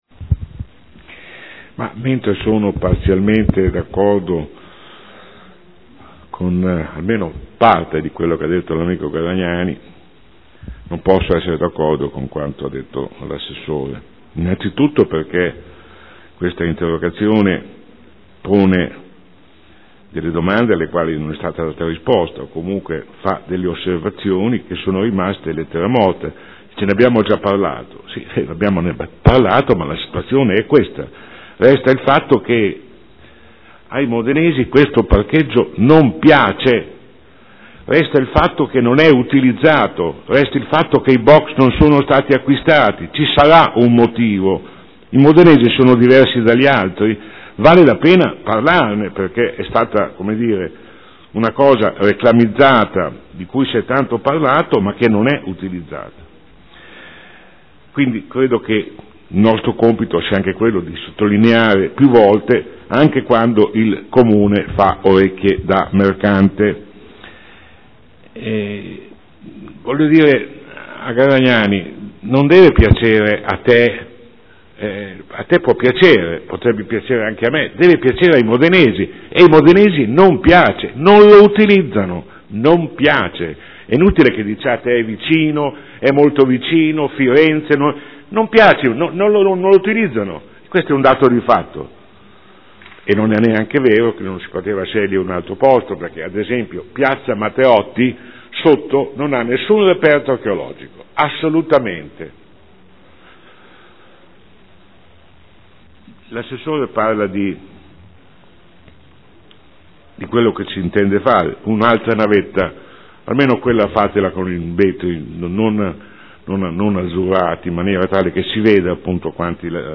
Sandro Bellei — Sito Audio Consiglio Comunale